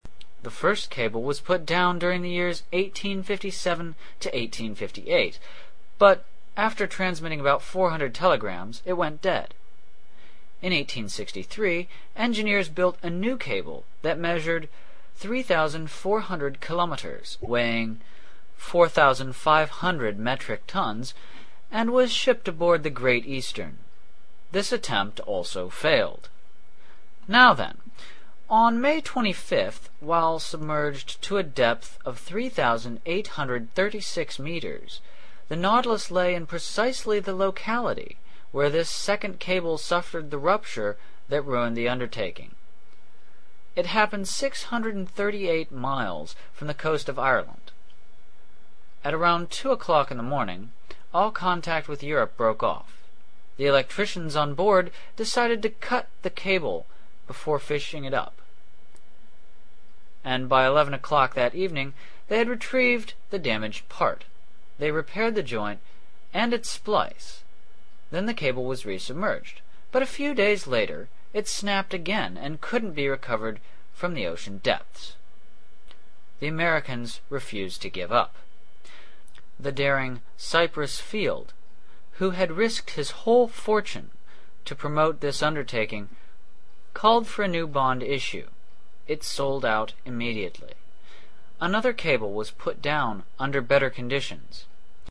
英语听书《海底两万里》第532期 第33章 北纬47.24度, 西经17.28度(7) 听力文件下载—在线英语听力室